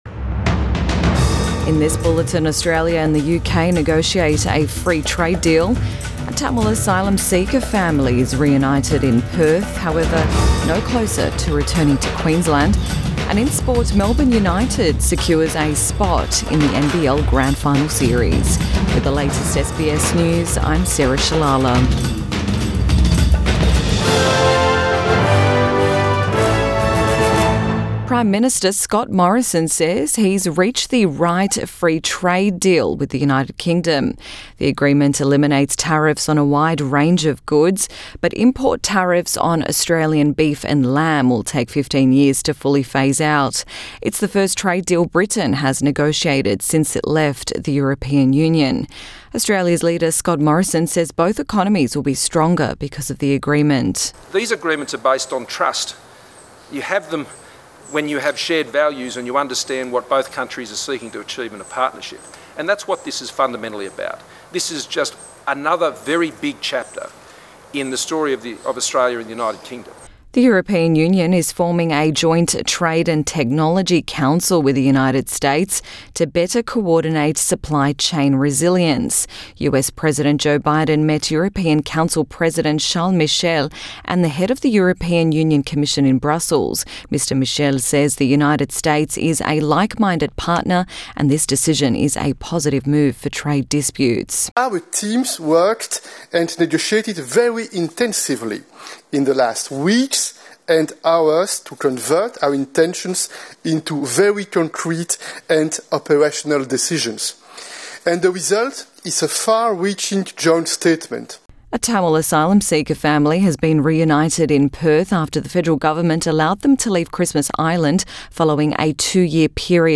AM Bulletin 16 June 2021